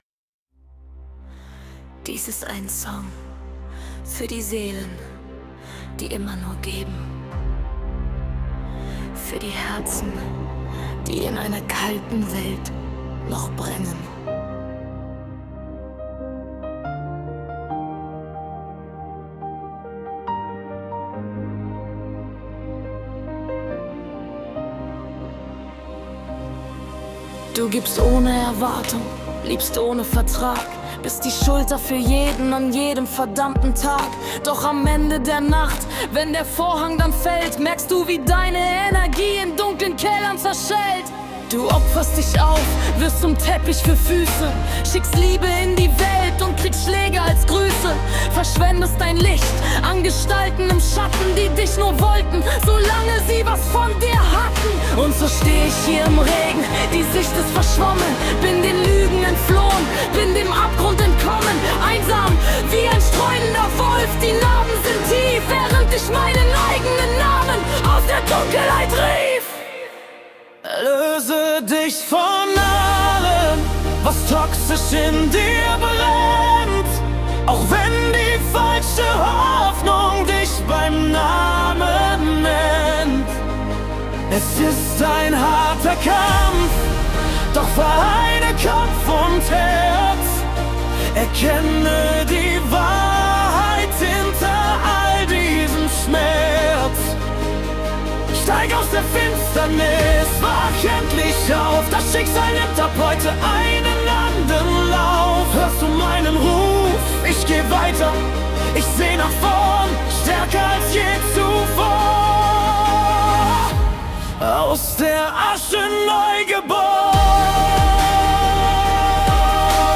Trance, MaleVoice